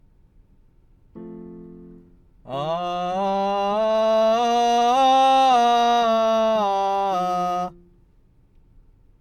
音量注意！
しっかりと声量を確保できる音域まで落として、狙った音高に到達した上で声量をなるべく大きくできていれば、ひとまず地声の練習としてはOKです。